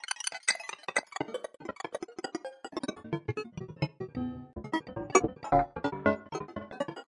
拨浪鼓声集2》F48D
描述：由金属声源开发的快速变化的极高范围的声音元素序列，高过滤的噪音声音为主，F48C.
标签： 金属 噪声 嘎嘎
声道立体声